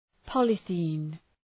{‘pɒlıɵi:n}
polythene.mp3